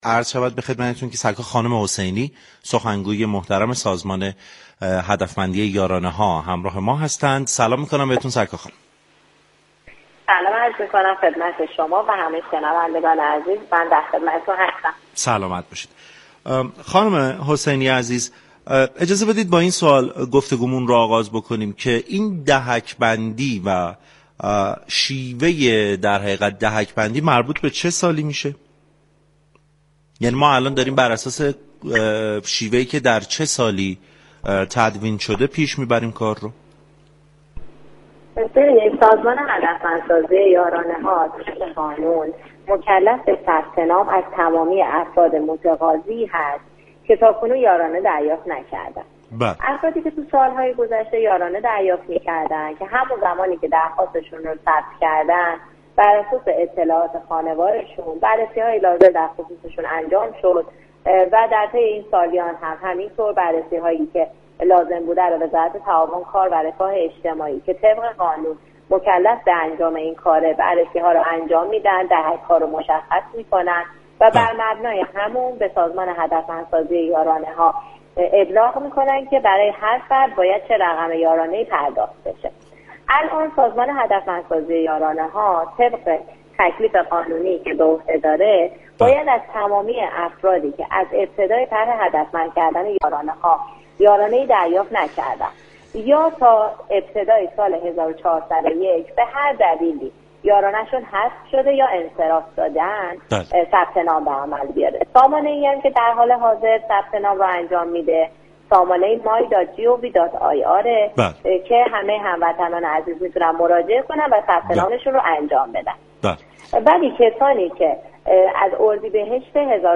گفت و گو با برنامه سعادت آباد رادیو تهران